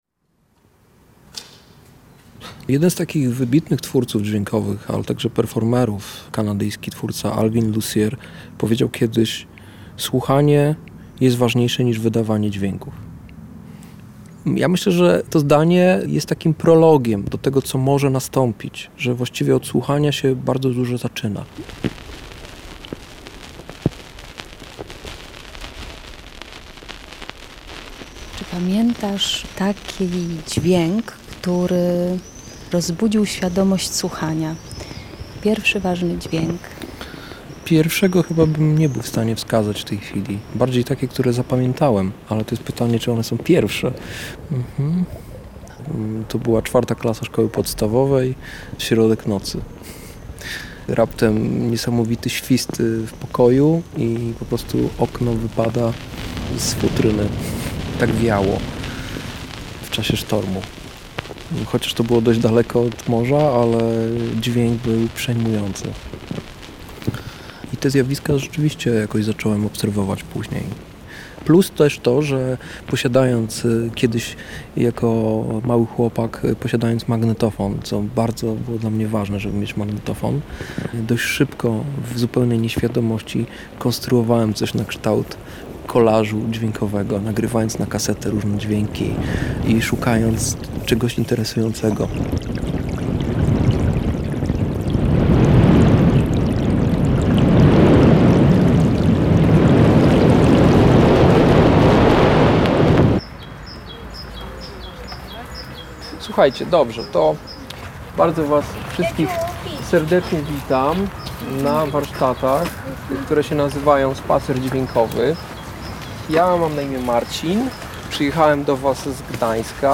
Reportaż
Wspólnie wybierzemy się na spacer dźwiękowy dla rodzin